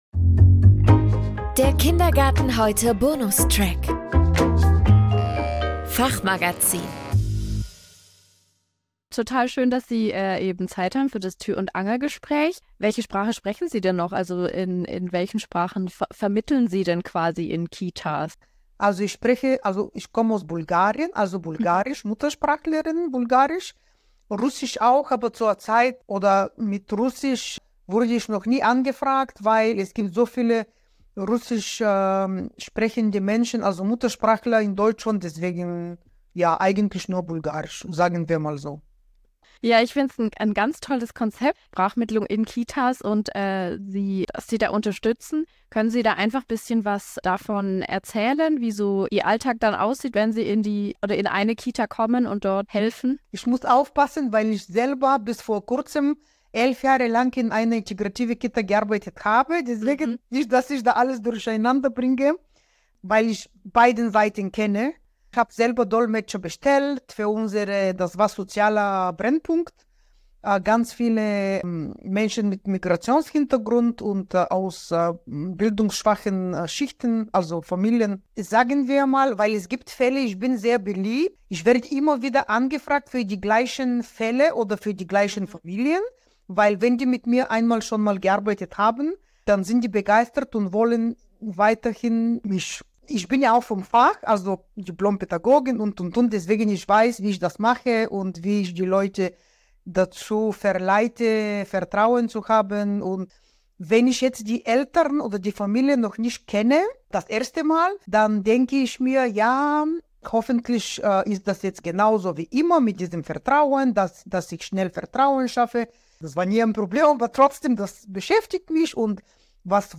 Ein Interview mit einer Sprachmittlerin